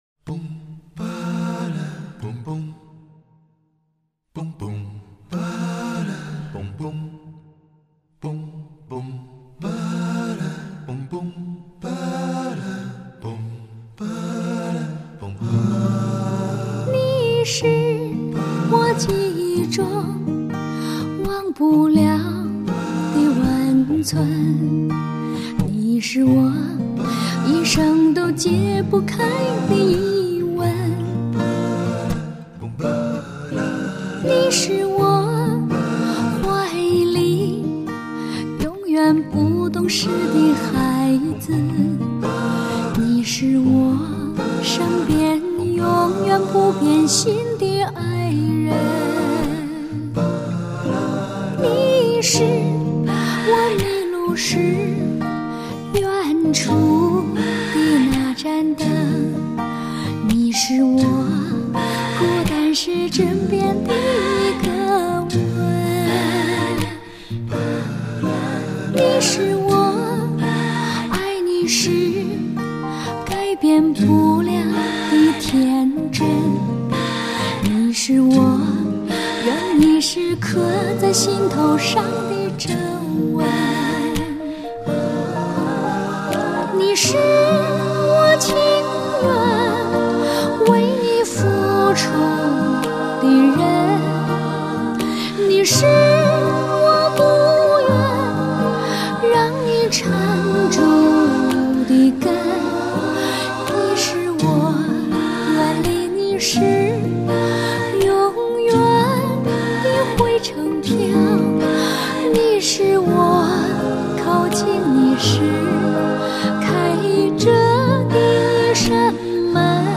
温馨 浪漫 时尚
片尾曲